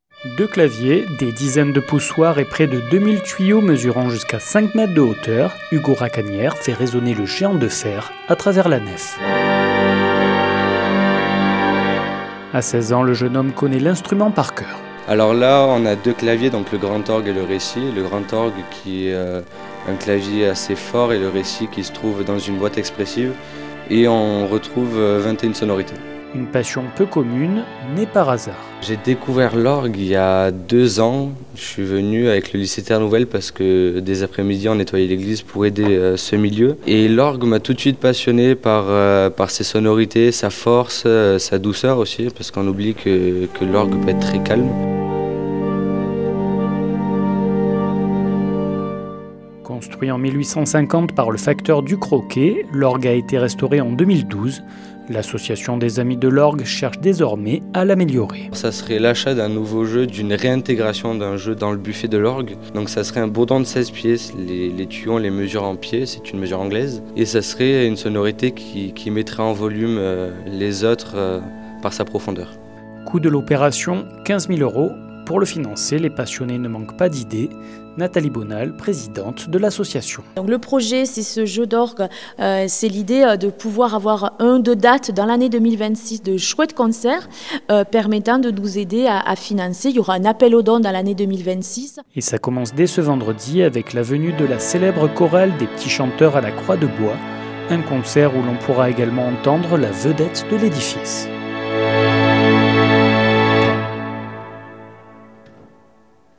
La poignée de passionnés lance donc un appel à la générosité. 48FM s’est rendue sur place, au plus près de l’instrument.
Reportage